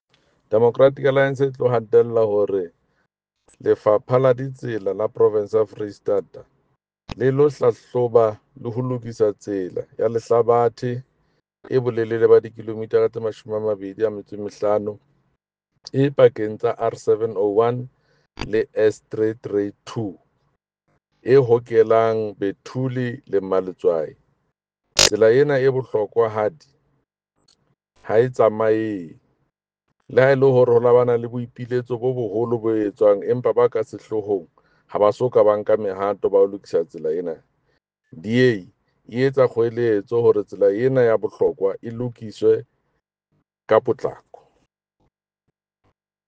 Sesotho soundbite by Jafta Mokoena MPL with pictures here, here, and here